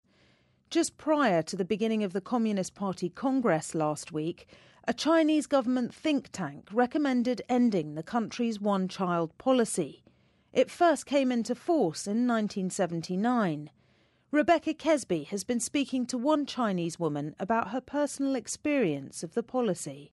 在线英语听力室【英音模仿秀】只生一个好？